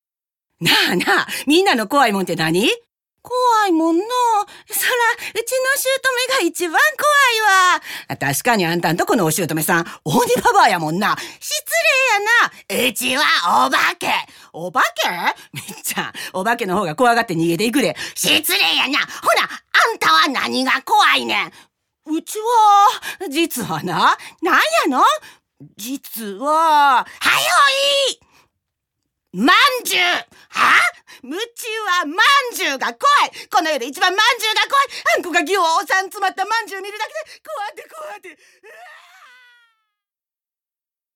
ボイスサンプル
3.新録 昭和風NA